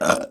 Hombre eructando 1
Sonidos: Acciones humanas Sonidos: Voz humana